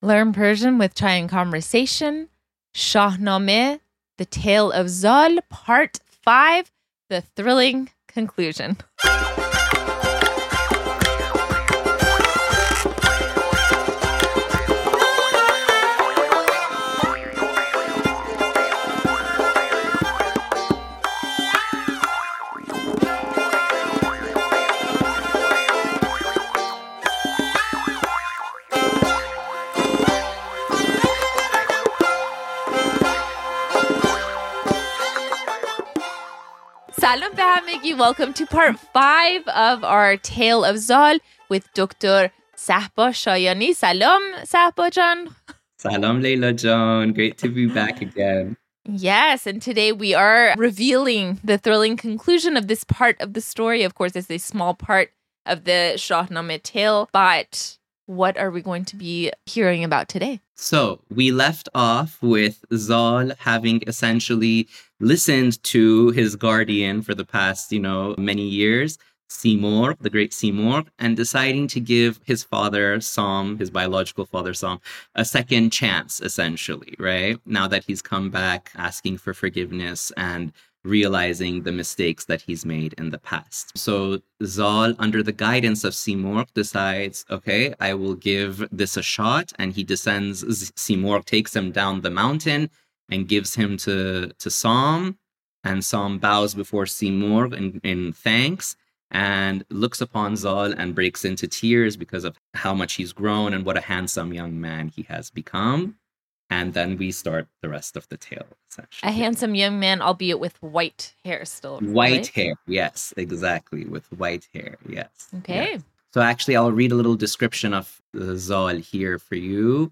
Lesson 99: Shahnameh, Part 5 - Learn Conversational Persian (Farsi)